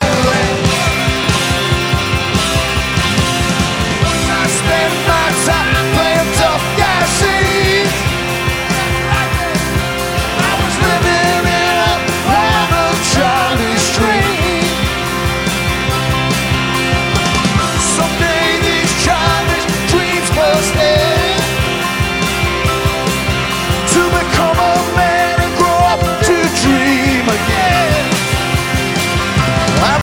Lead vocal, electric and acoustic guitars, harmonica
Piano, keyboards, accordion
Tenor and baritone saxophones, percussion, backing vocal